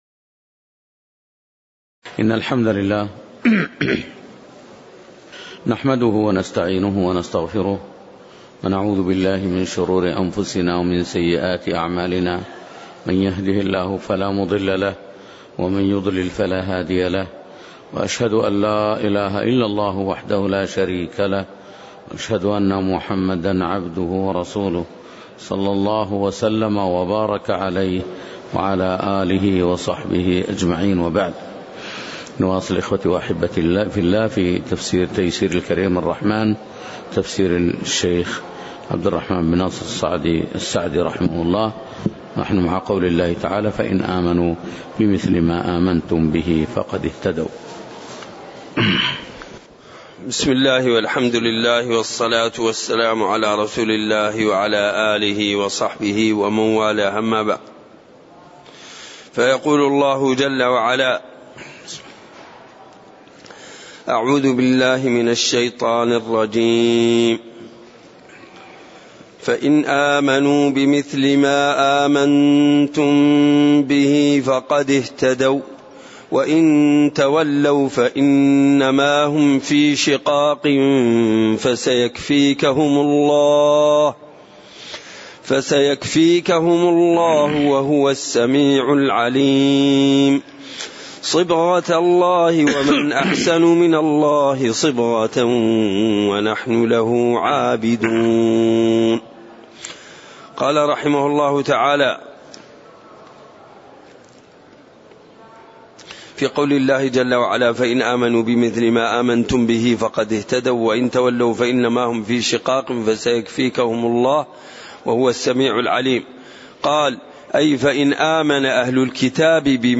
تاريخ النشر ٢١ جمادى الأولى ١٤٣٨ هـ المكان: المسجد النبوي الشيخ